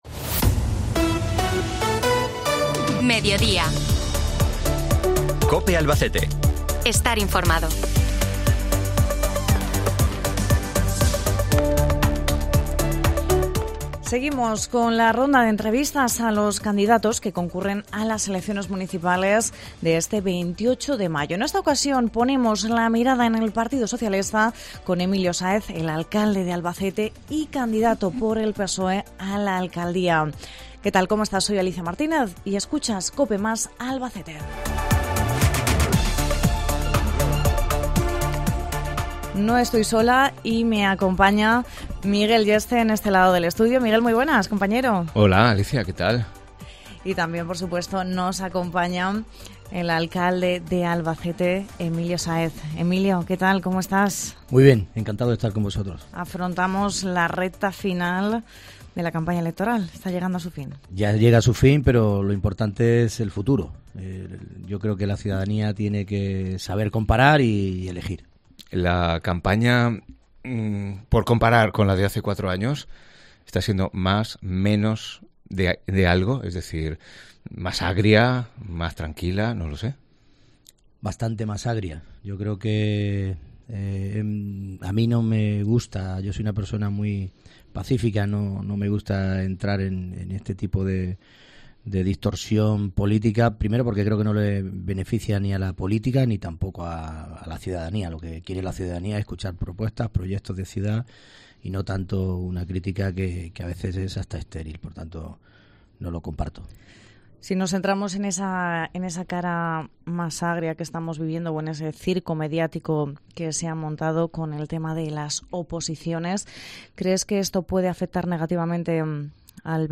Seguimos con la ronda de entrevistas a los candidatos que concurren a las elecciones municipales del 28 de mayo. En esta ocasión, ponemos la mirada en el partido socialista, con Emilio Sáez a la cabeza, actual alcalde de Albacete y candidato por el PSOE a la alcaldía.